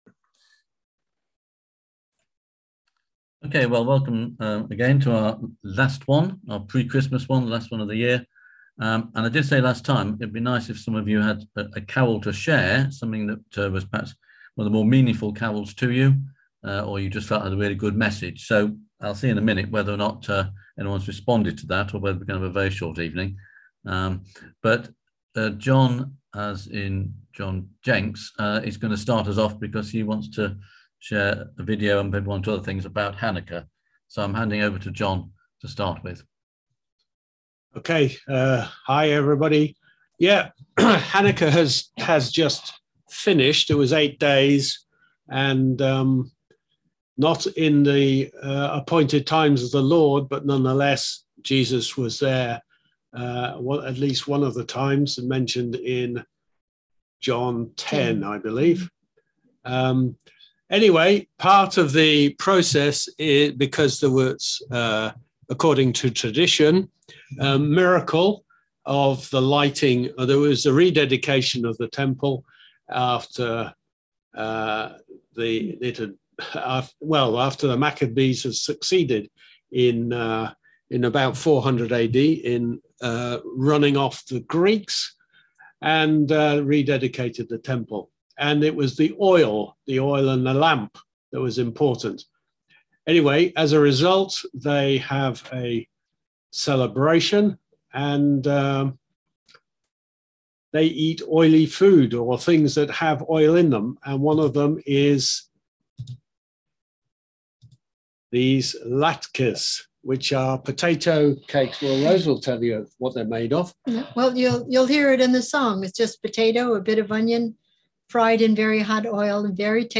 On December 9th at 7pm – 8:30pm on ZOOM